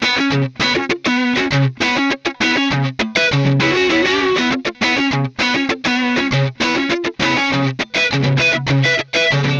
Вот я скинул файл, тут записан звук напрямую из DAW output>ADCinput. Тут звучит как по идее должно быть при баунсе файла.
Вложения Gtr test Pi in DAW.wav Gtr test Pi in DAW.wav 2,4 MB · Просмотры: 193